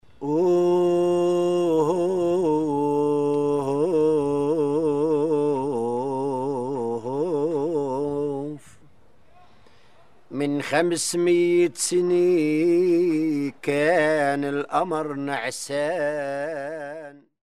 Three men seated outdoors under a tree perform traditional Lebanese zajal, with one playing a frame drum and the others clapping, surrounded by seasonal fruits on a wooden table.
Zajal embodies the power of language as living heritage – transmitted through performance, dialogue, and communal gathering.
Lebanese-Zajal.mp3